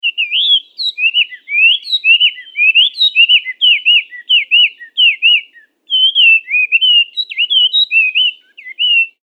自然・動物 （58件）
ガビチョウ.mp3